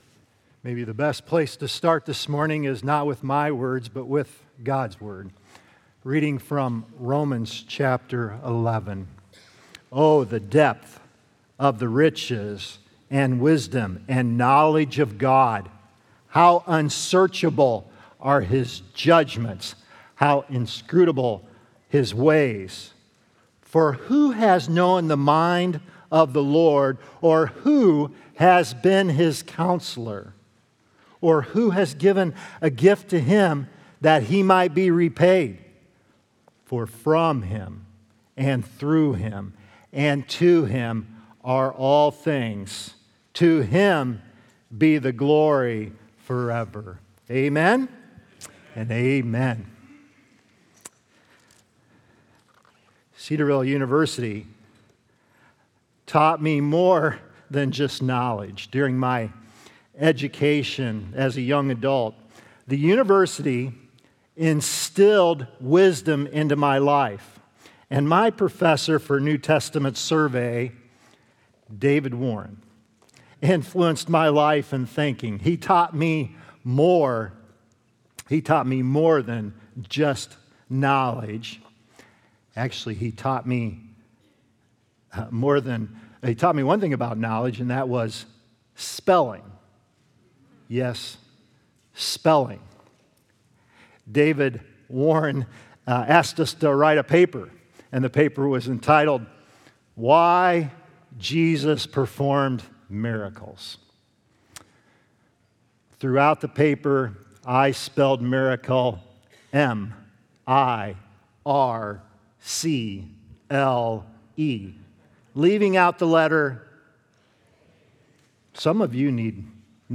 God Is Always Wise | Baptist Church in Jamestown, Ohio, dedicated to a spirit of unity, prayer, and spiritual growth